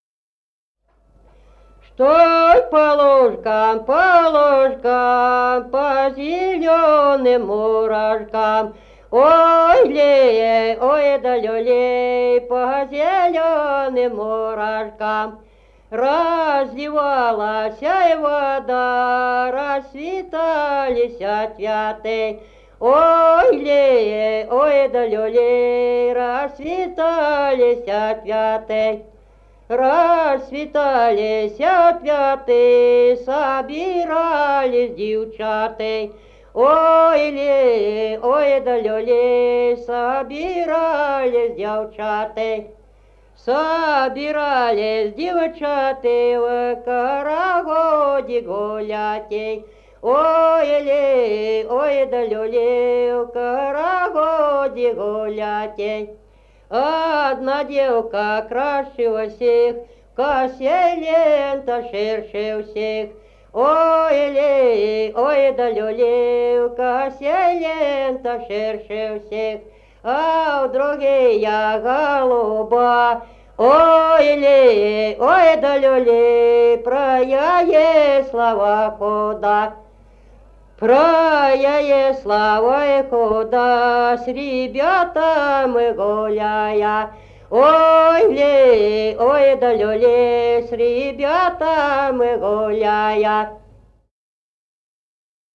Народные песни Стародубского района «Что по лужкам», карагодная.
1953 г., д. Камень.